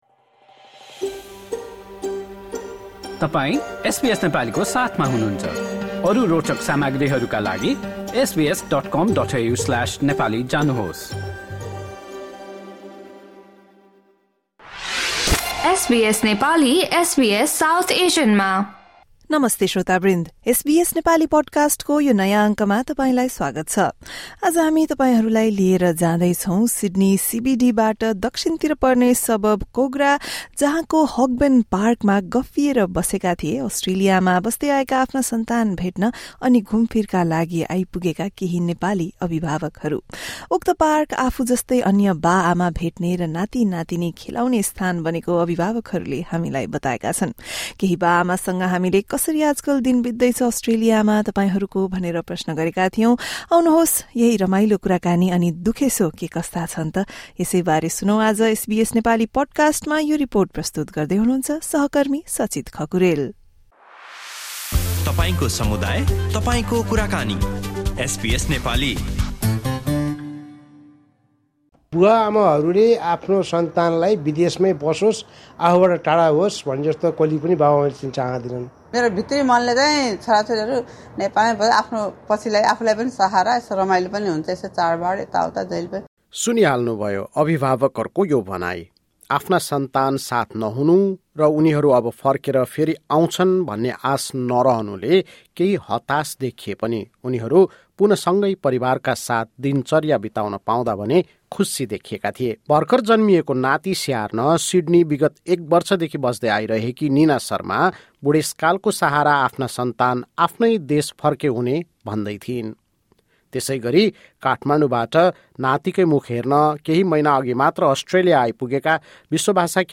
In this episode of the SBS Nepali podcast, we speak with Nepali parents visiting Australia to meet their diaspora families. Some have travelled to see their children living far from home, while others are meeting their grandchildren for the first time. They say that although they miss their children in Nepal, they also feel proud of the progress their children have made overseas.